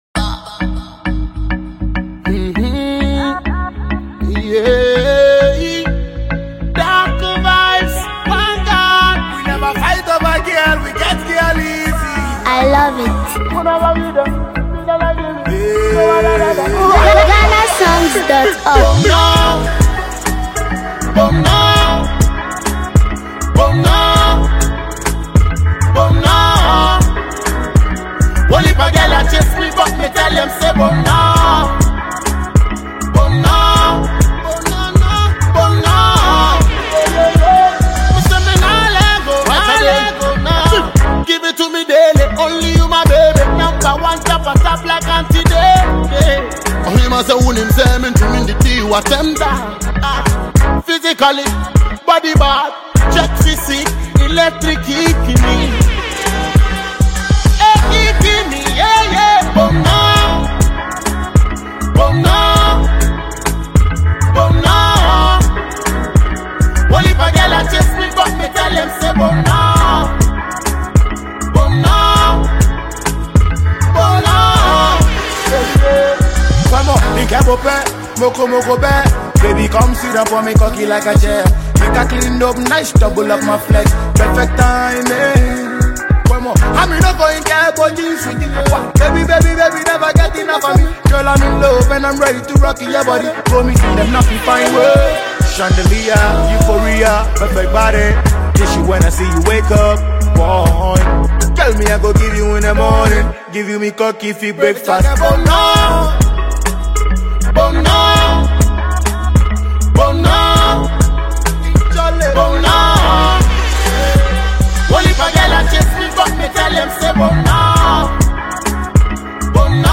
smooth vocals and catchy melodies